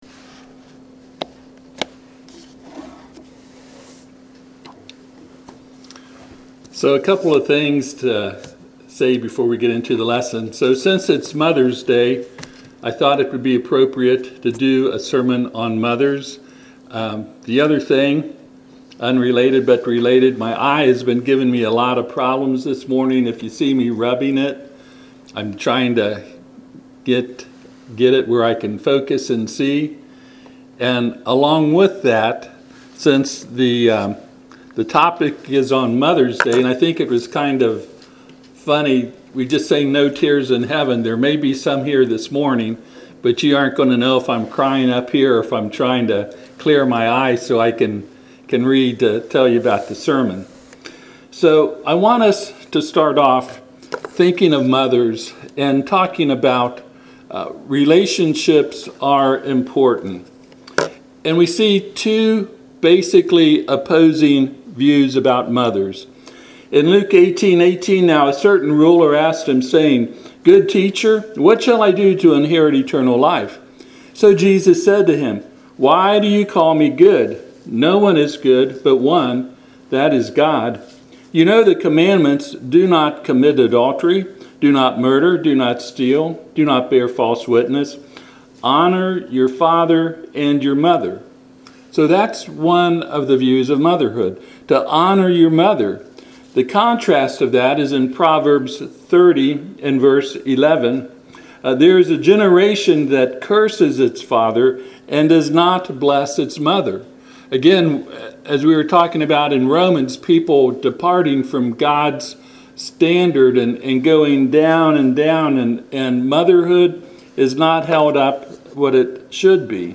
Passage: Luke 8:20-21 Service Type: Sunday AM